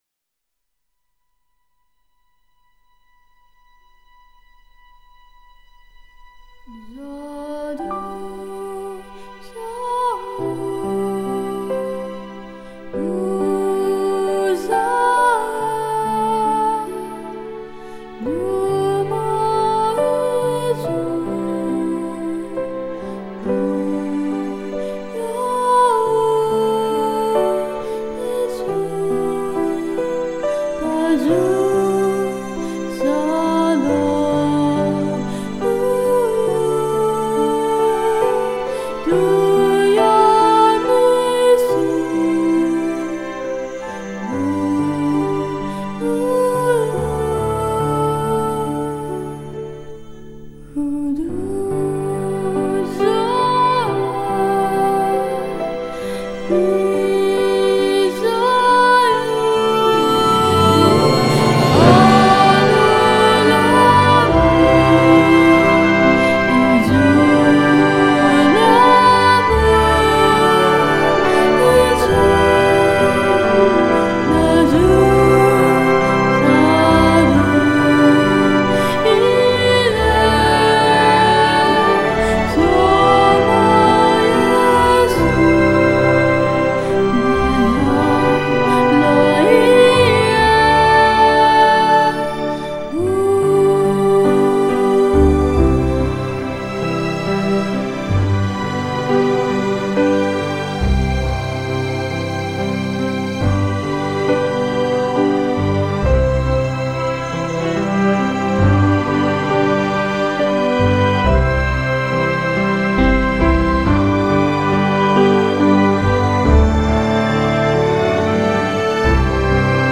看电影的时候，空灵的声音和梁朝伟绝望的眼神，很震撼...
以歌声清脆而著名
这首歌感情是用哭着唱的，搞得人心碎！